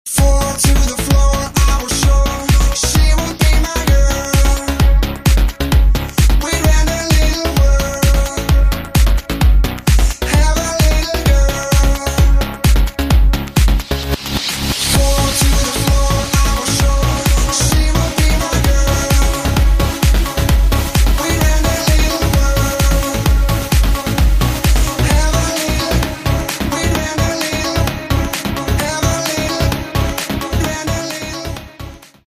• Качество: 128, Stereo
громкие
dance
электронная музыка
красивая мелодия
club